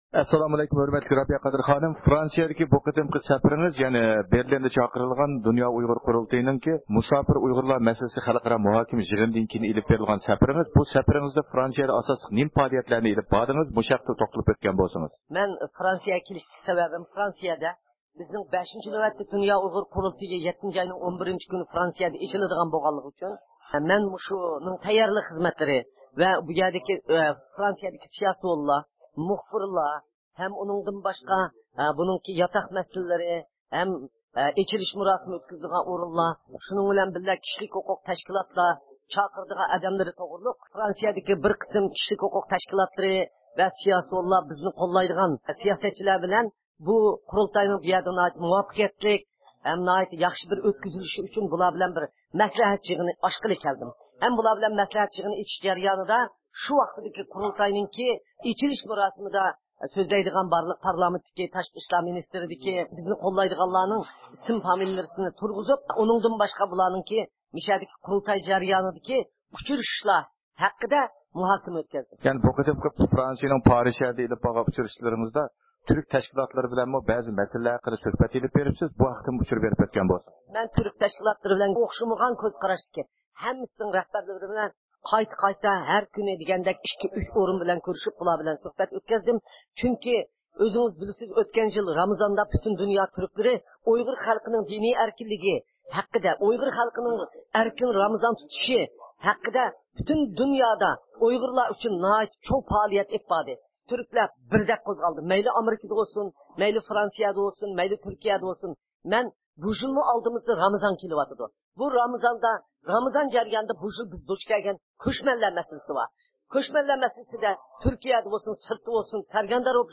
يۇقىرىقى ئاۋاز ئۇلىنىشىدىن رابىيە قادىر خانىم بىلەن بۇ ھەقتە ئېلىپ بارغان سۆھبىتىمىزنىڭ تەپسىلاتىنى ئاڭلاڭ.